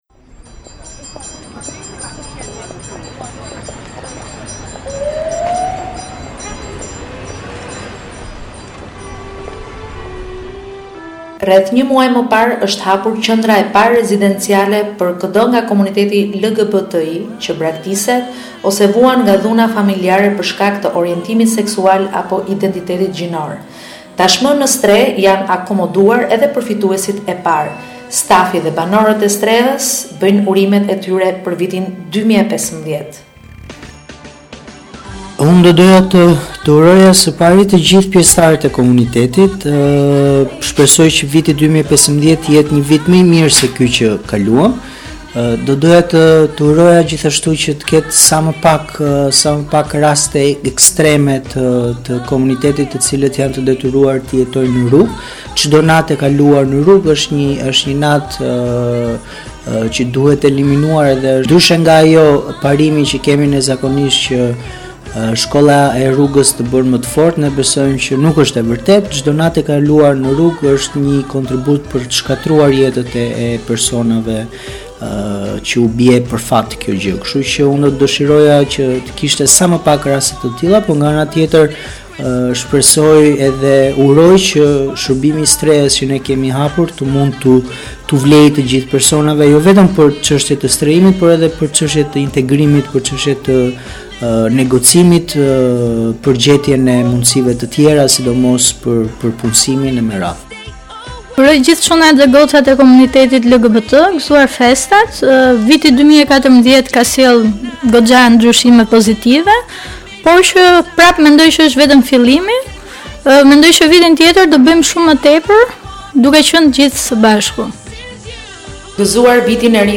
Tashmë në Strehë janë akomoduar edhe përfituesit e parë. Stafi dhe banorët e Strehës bëjnë urimet e tyre për vitin 2015.